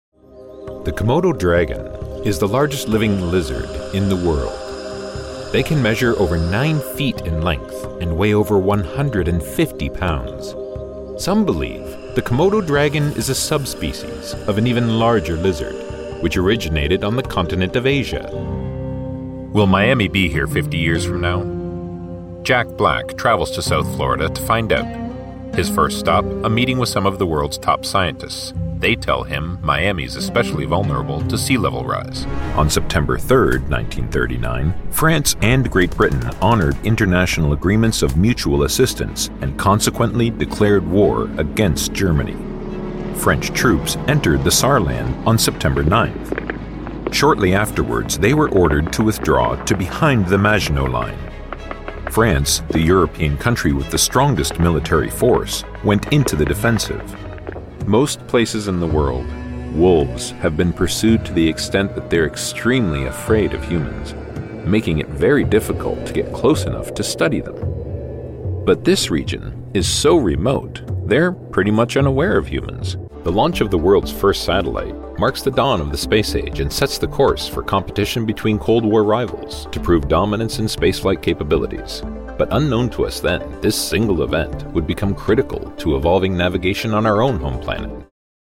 a resonant, warm baritone voice with a neutral Canadian accent
Documentaries
My dedicated, broadcast-quality studio is Source Connect Certified* and features a Neumann TLM103 microphone, a Universal Audio Apollo X preamp, Audio Technical M50x Studio Headset, MacBook Pro running Adobe Audition, and a hard-wired ethernet connection with 1.5G speed.